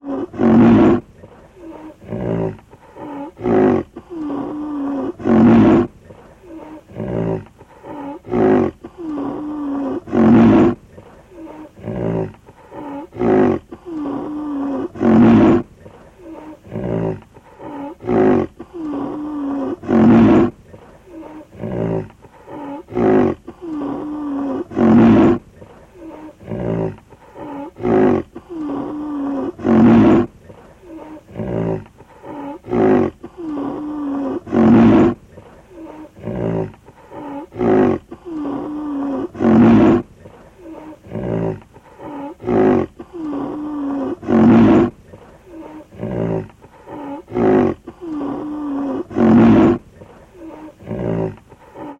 Звуки носорога
Носорог хрипло кряхтит